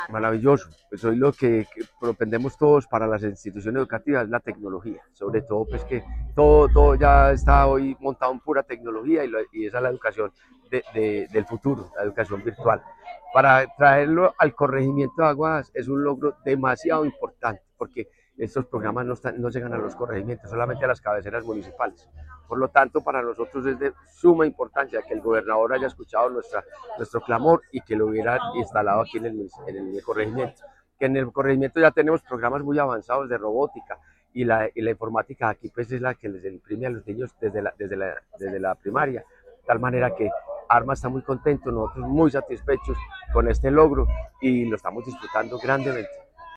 Alcalde de Aguadas, Fabio Gómez Mejía.
Alcalde-de-Aguadas-Fabio-Gomez-Mejia-aula-STEM.mp3